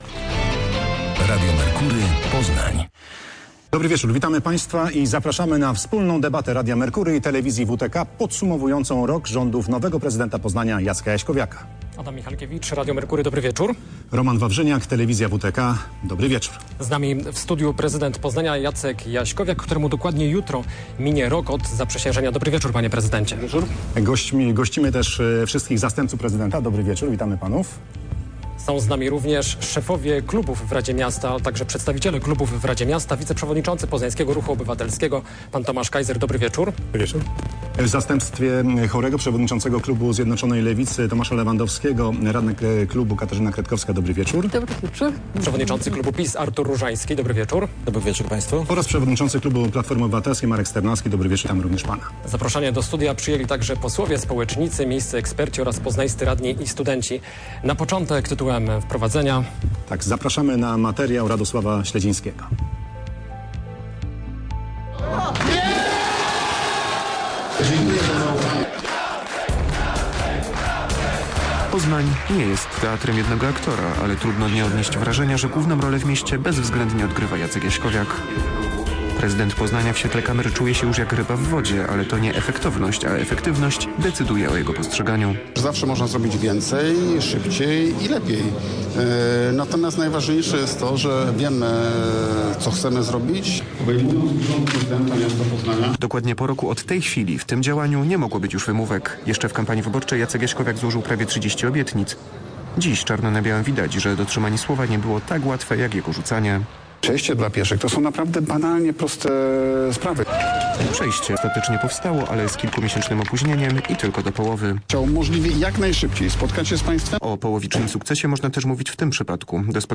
Jacek Jaśkowiak po roku. Debata
Rok prezydentury Jacka Jaśkowiakia podsumowali dziennikarze Radia Merkury i telewizji WTK.